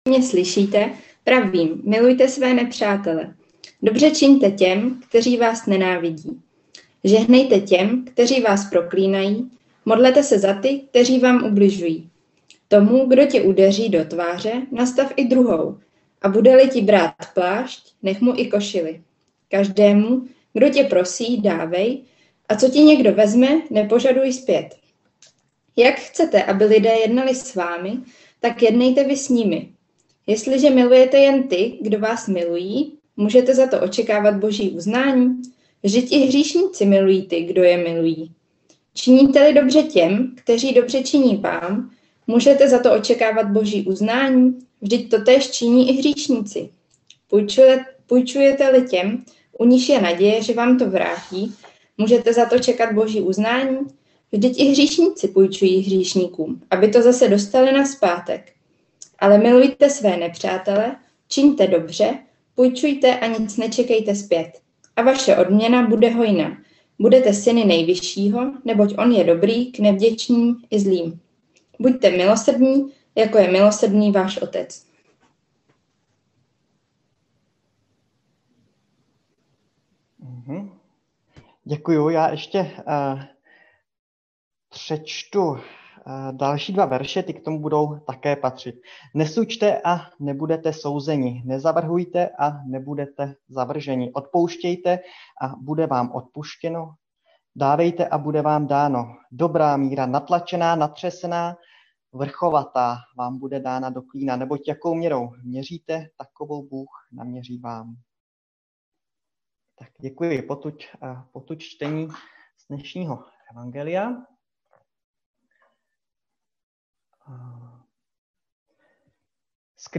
Nedělní kázání 17.1.2021